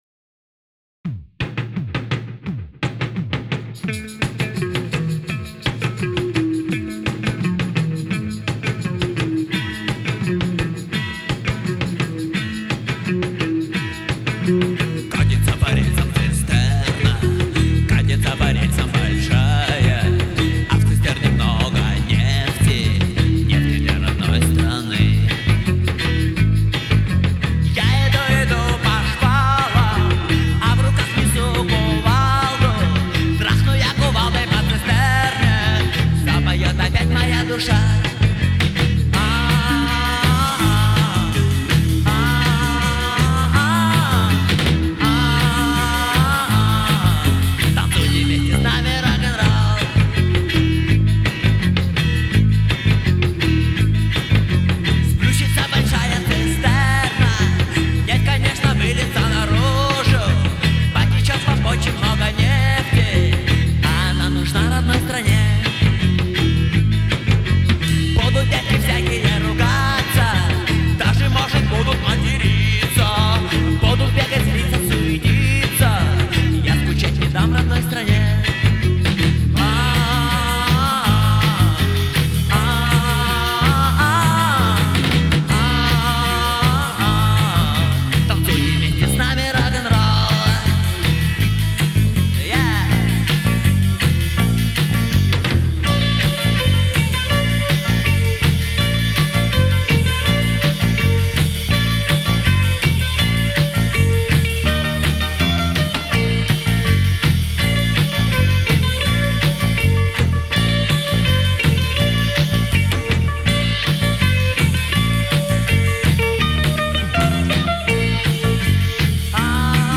Альбом записан летом 1991 года в Хабаровском Горводоканале
клавишные
барабаны
гитара, вокал
губная гармошка, тамбурин